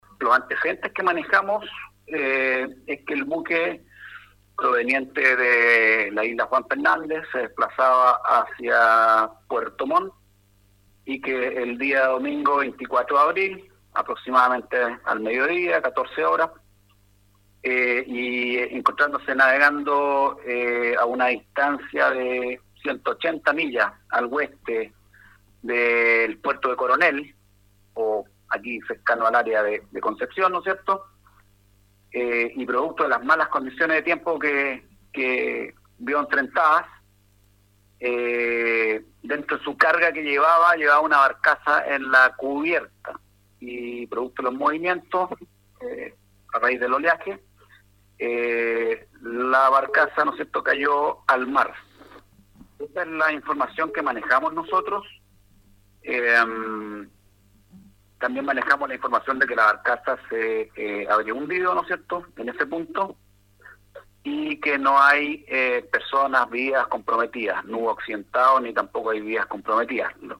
Sobre el accidente, la información la entregó sobre el accidente el capitán de Navío Javier Mardones, Gobernador Marítimo de Talcahuano.
29-GOBERNADOR-MARITIMO-TALCAHUANO.mp3